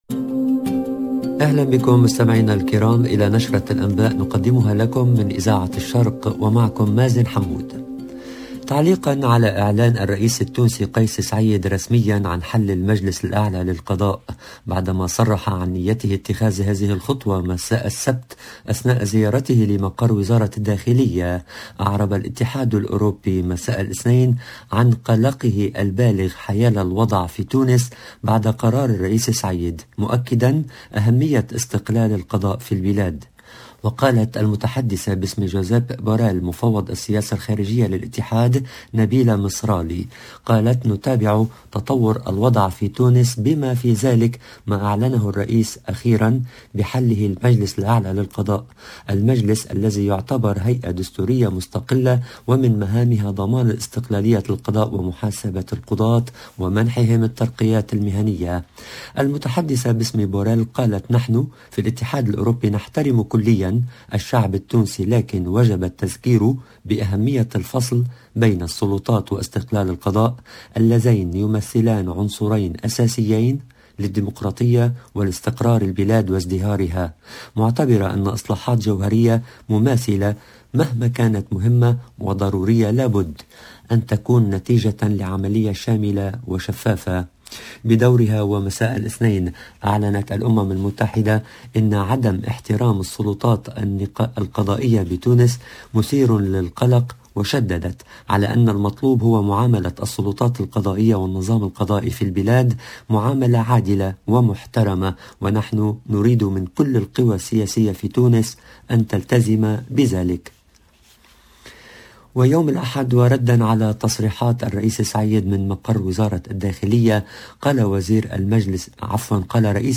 LE JOURNAL DE MIDI 30 EN LANGUE ARABE DU 8/02/22